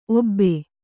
♪ obbe